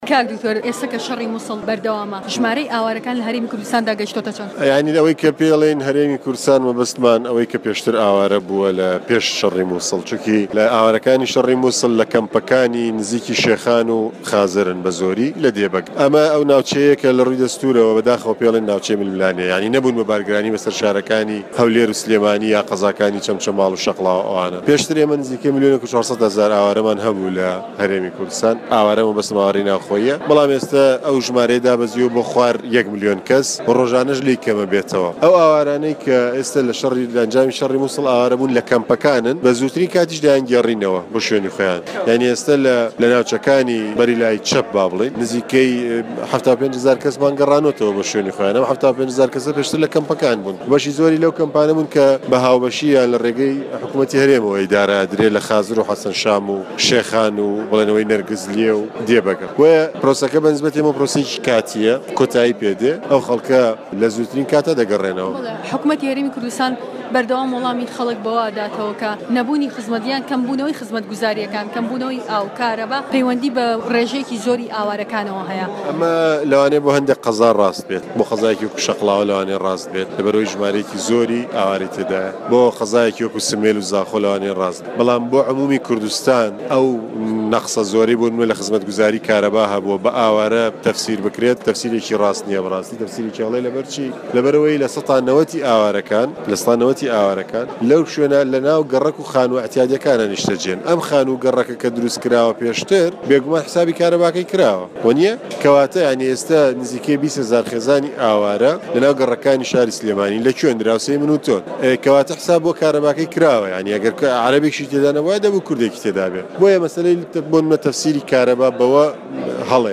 وتووێژ لەگەل دکتۆر دەرباز